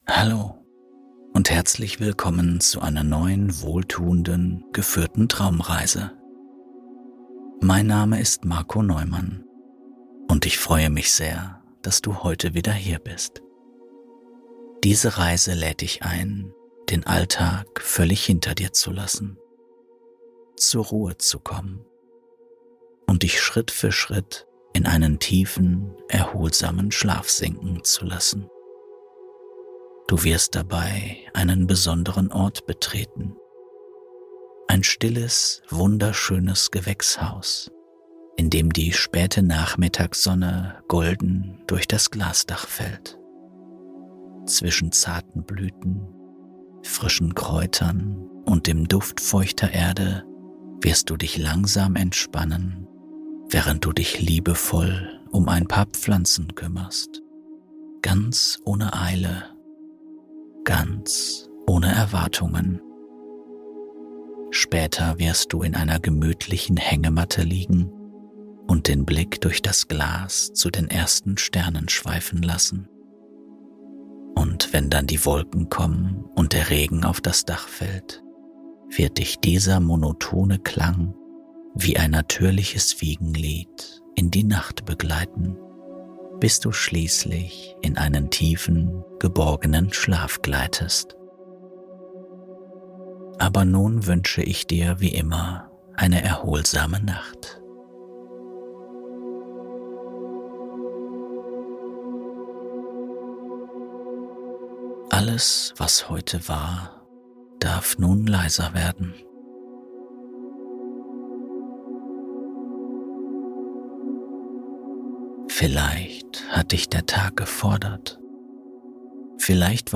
Das Gewächshaus-Geheimnis: Warum JEDER in 5 Minuten einschläft! + Regen